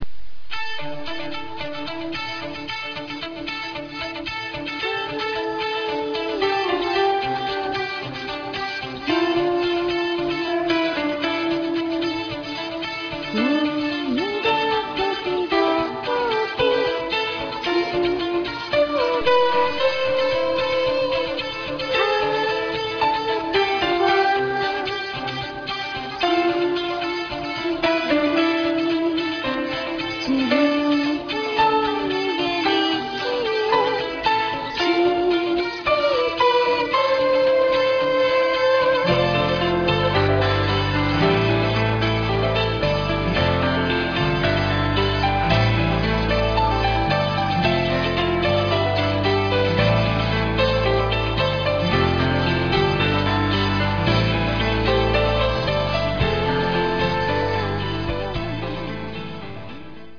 ( 主唱兼鍵盤 )
( 女主唱 )
( RAPPER )